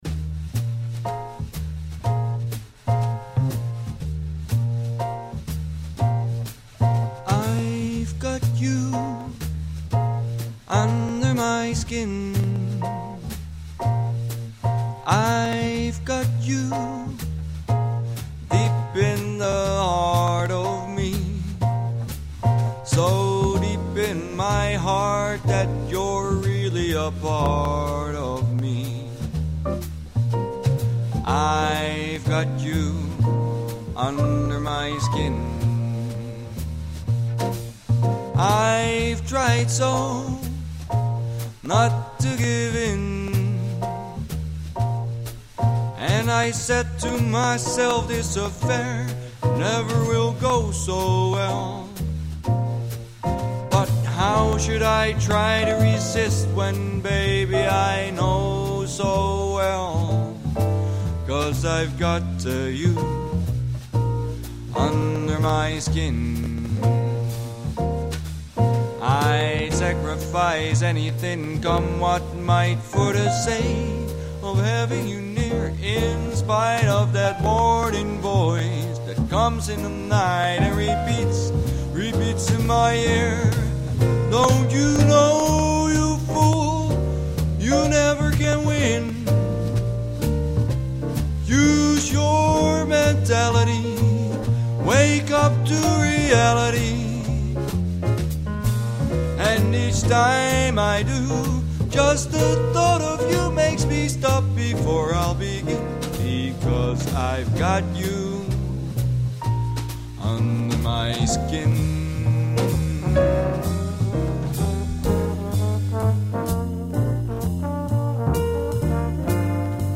quick and dirty version
unedited single take recordings for vocals and trombone